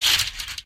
sounds / material / human / step